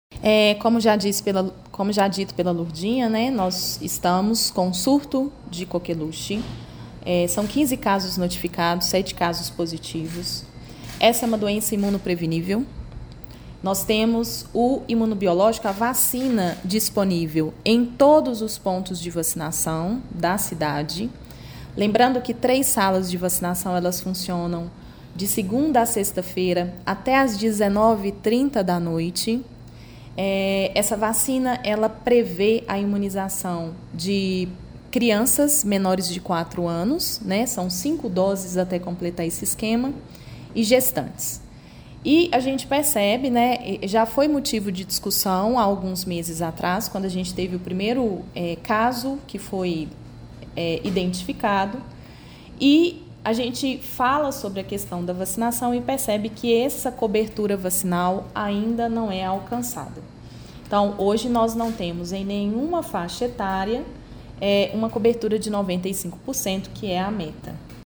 A secretária municipal de Saúde, Ana Clara Teles Meytre, destaca o surto de coqueluche em Pará de Minas. Reafirma que a população precisa se vacinar contra a doença, inclusive, algumas salas de vacinação funcionam em horário estendido para a atender a todos: